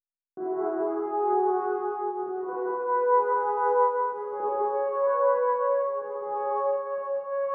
violons_studio_strings_01.wav